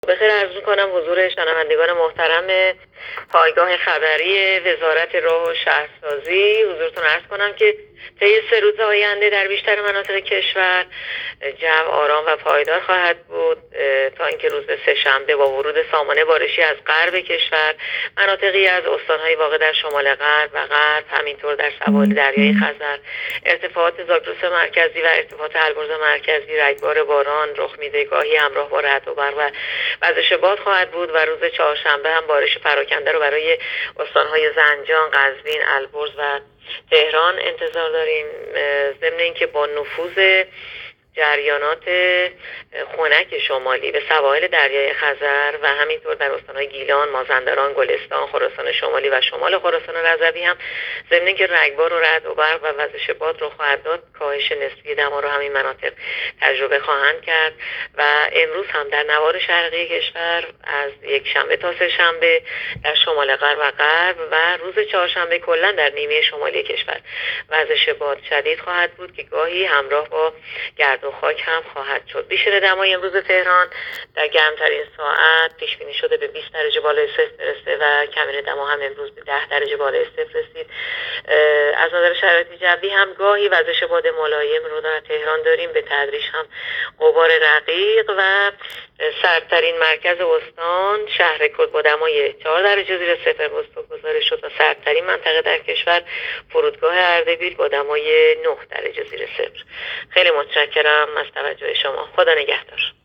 گزارش آخرین وضعیت جوی کشور را از رادیو اینترنتی پایگاه خبری وزارت راه و شهرسازی بشنوید.
گزارش رادیو اینترنتی از آخرین وضعیت آب و هوای دوم اسفند؛